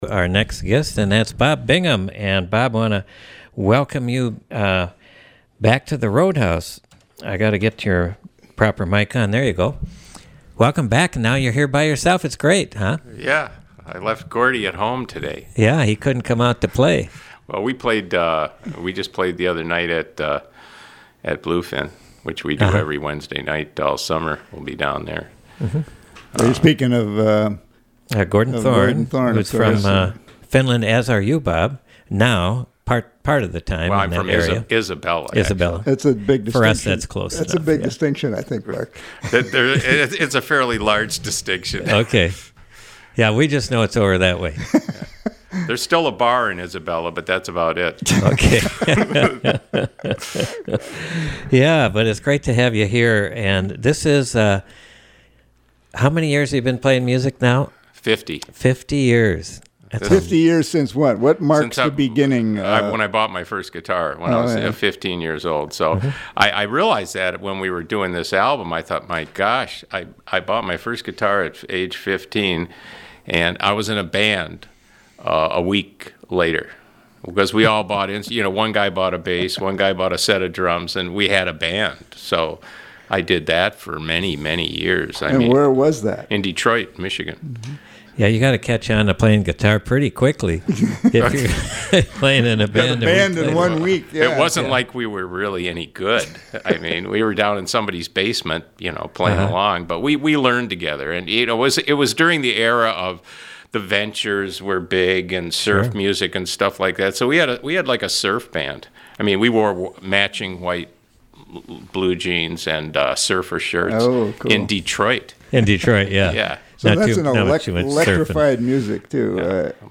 in Studio A
Fun stories abound in this interview